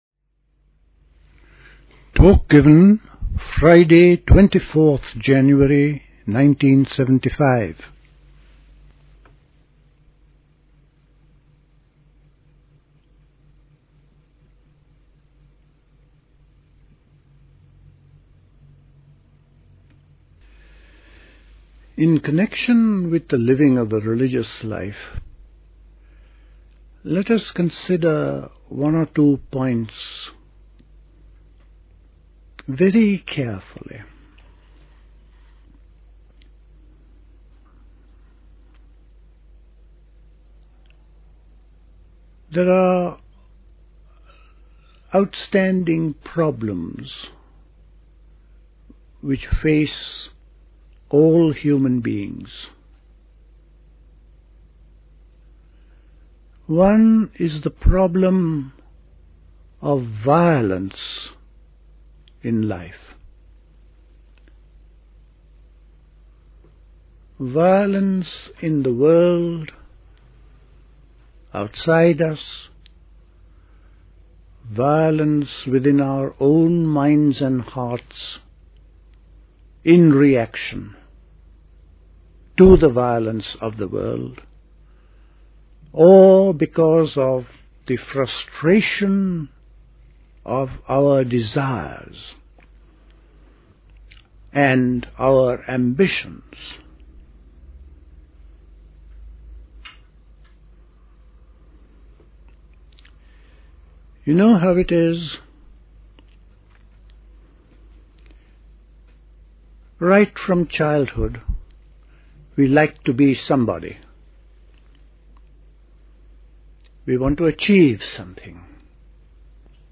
Play Talk